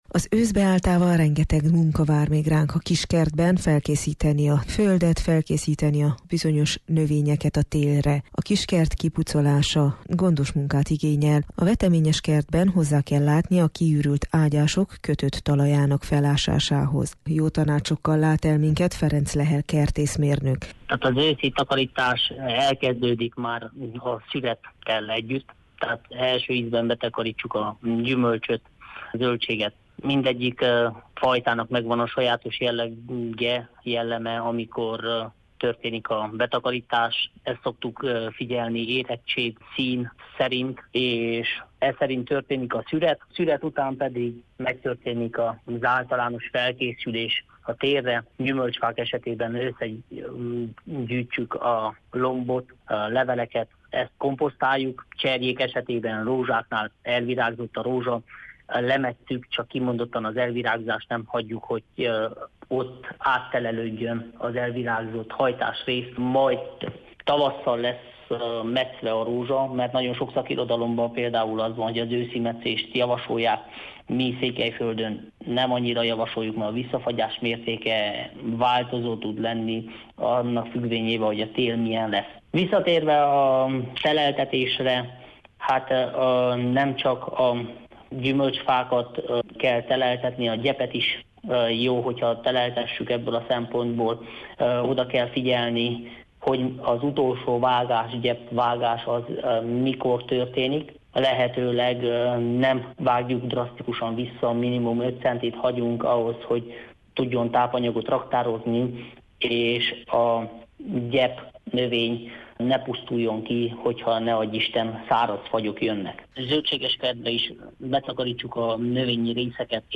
A Kertbarátok meghívottja, aki jótanácsokkal lát el minket